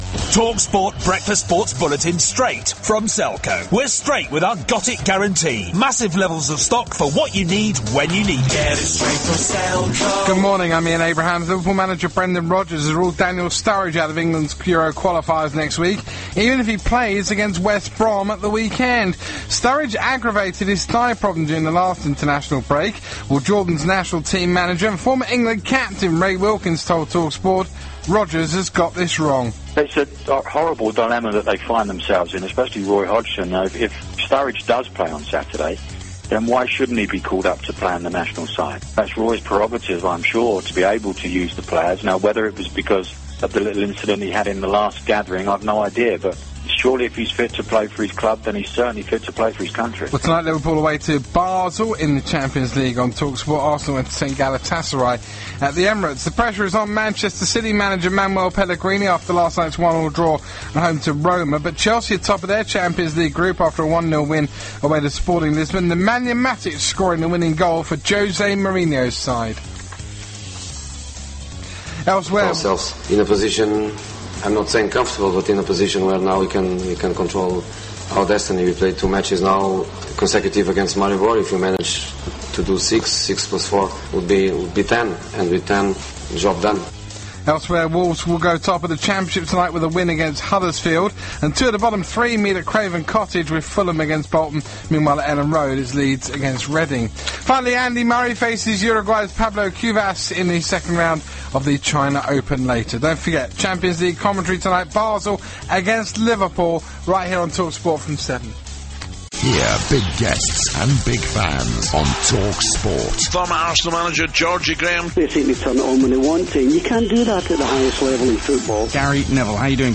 UK Sport Bulletin (October 1) Via TalkSport